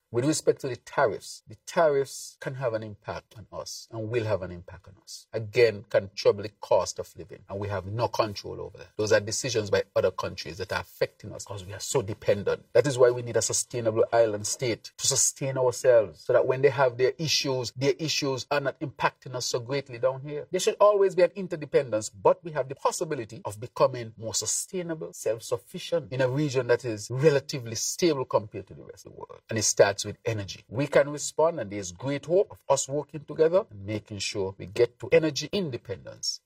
Prime Minister, and Minister of Finance, National Security, ET. al., the Hon. Dr. Terrance Drew, gave remarks surrounding US Tariffs on Chinese, Mexican and Canadian imports, during his latest “Roundtable” discussion with the media: